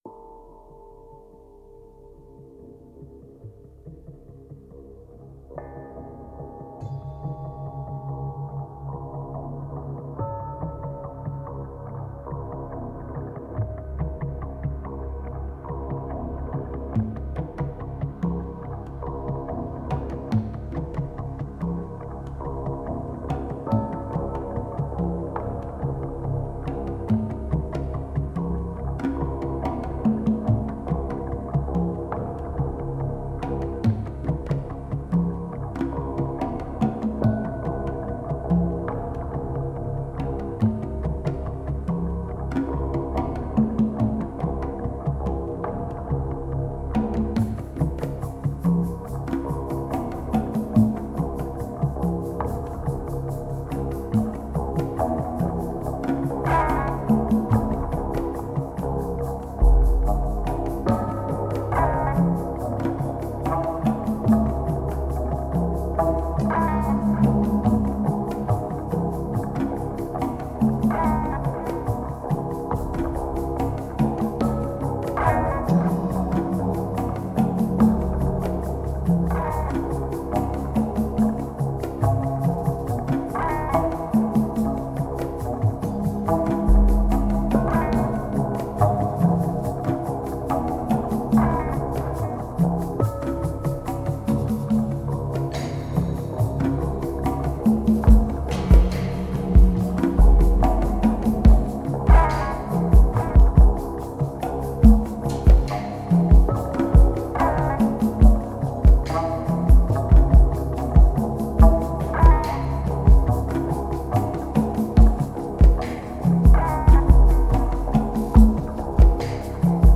Genre: Downtempo, Ambient.